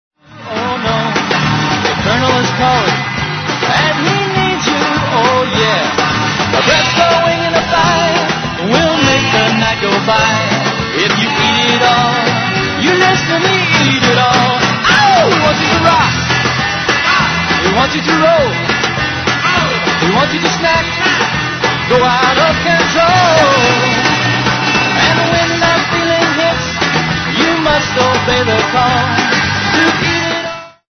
revamped and cleaner sounding band
snak-rockin' songs